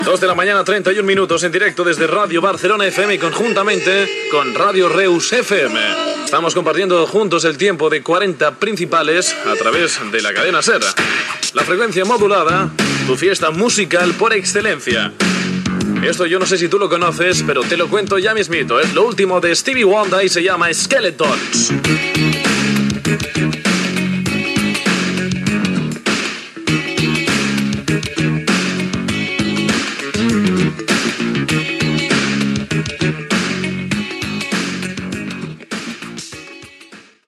Hora, identificació de les emissores connectades i tema musical
Musical